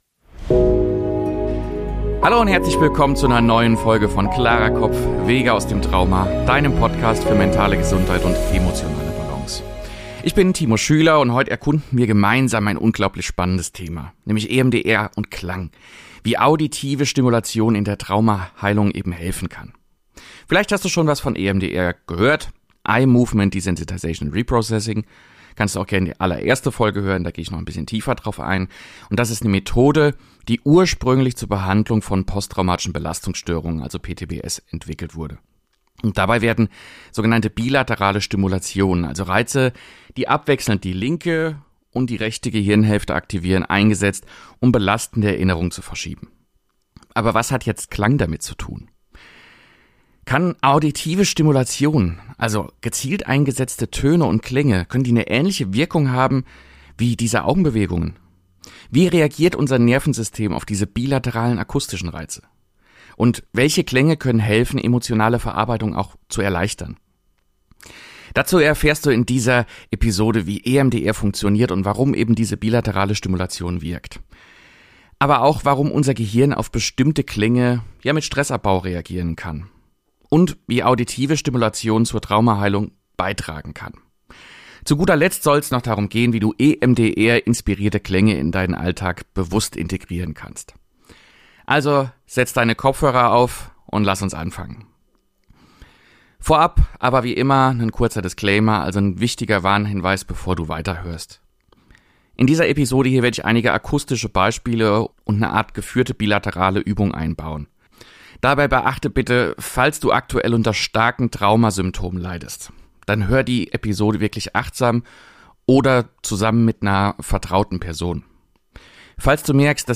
Eine praktische Mini-Session mit bilateralen Sounds!